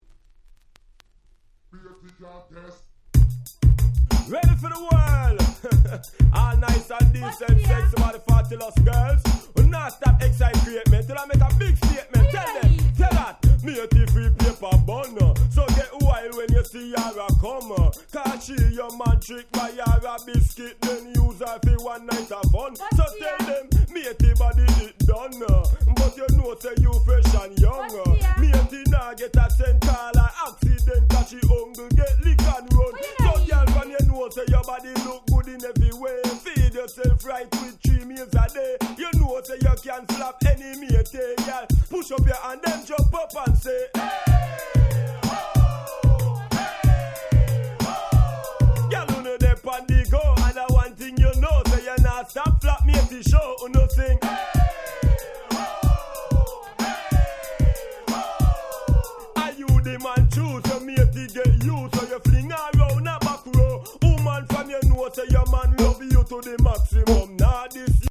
94' Nice Dancehall Reggae !!